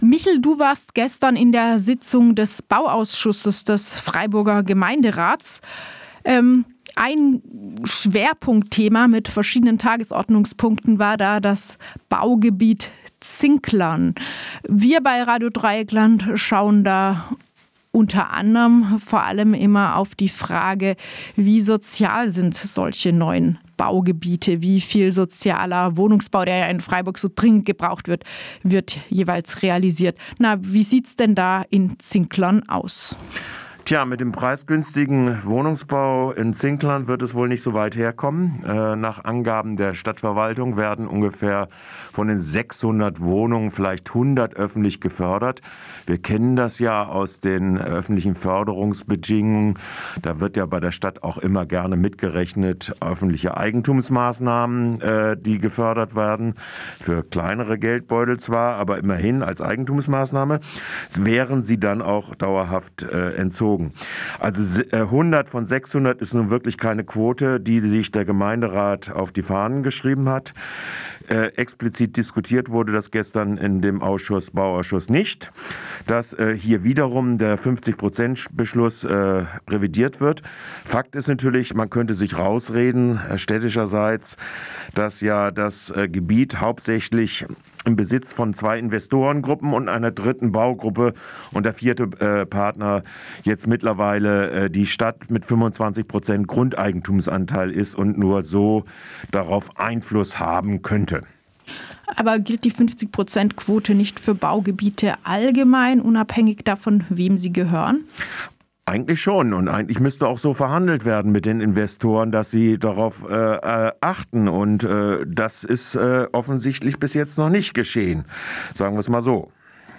Korresponentenbericht zu Themen des Bauauschuß